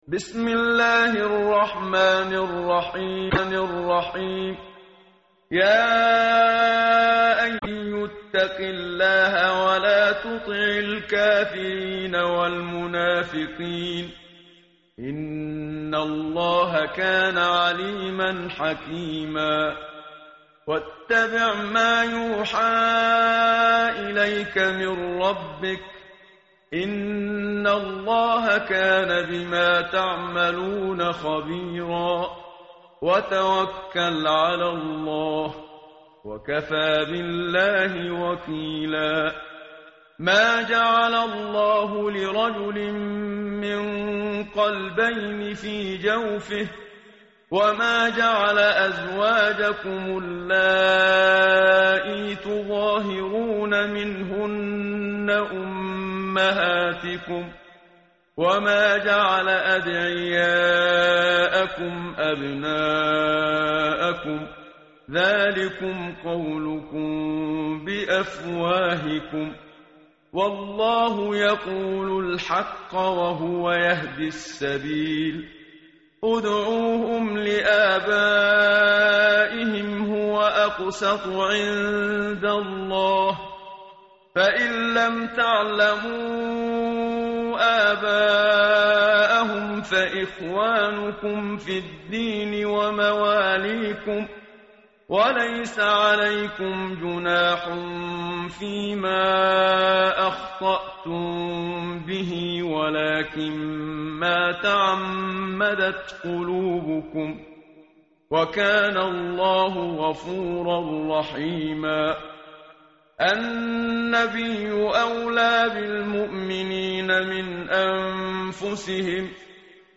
tartil_menshavi_page_418.mp3